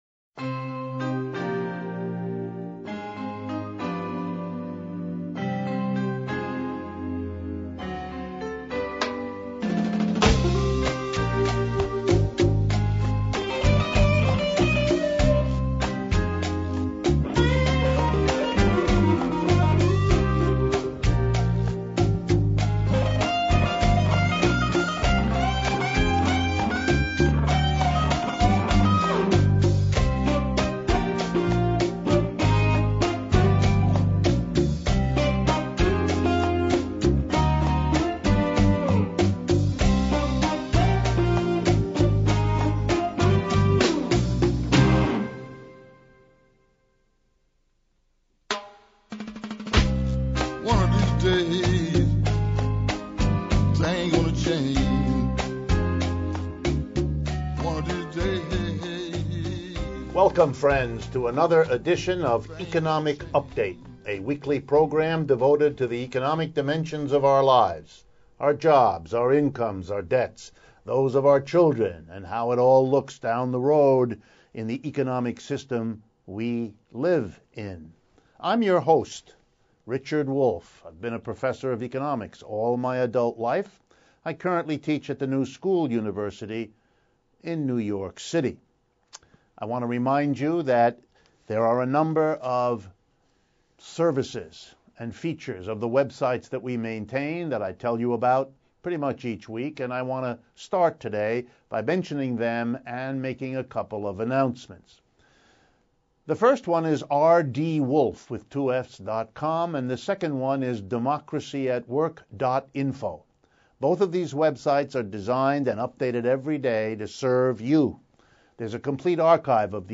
October 18, 2015 This week's edition of Economic Update features discussion on: saving capitalism, looming economic downturn, 250,000 against TTIP in Berlin, US plan to give Puerto Rican taxes to banks, and a one-on-one interview with Ms. Laura Flanders, an independent journalist on co-ops vs capitalism.